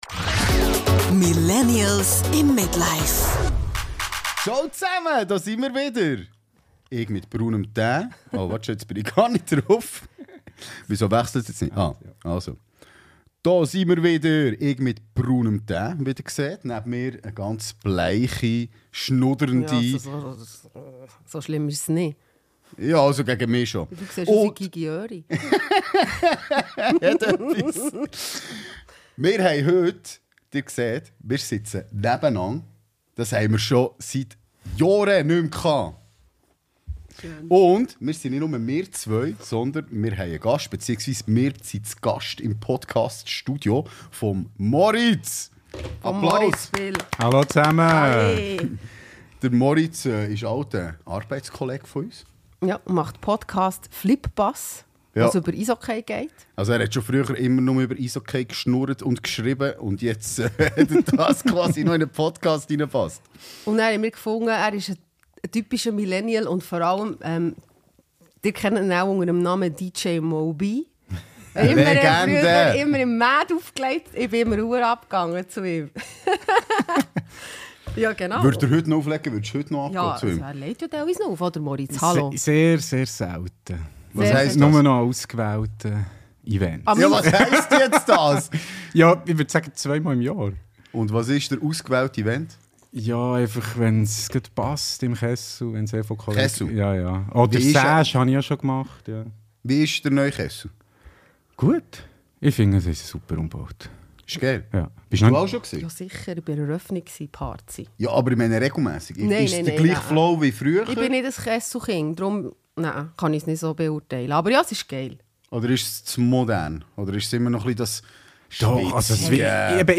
Alles anders also. Was gleich geblieben ist: Viel Millennial-Gelaber.